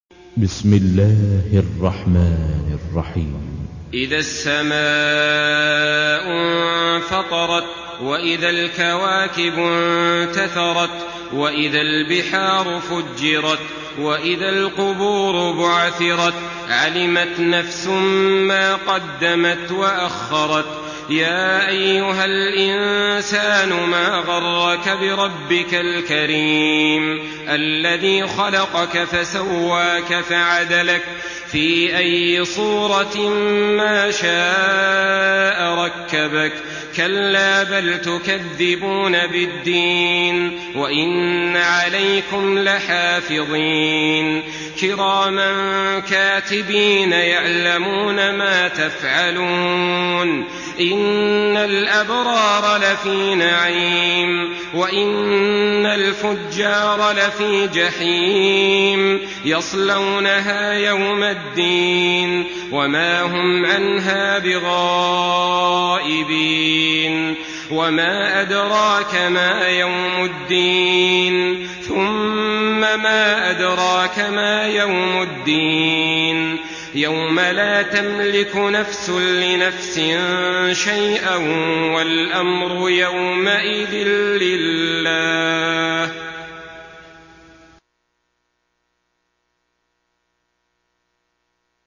Surah الانفطار MP3 by صالح آل طالب in حفص عن عاصم narration.
مرتل حفص عن عاصم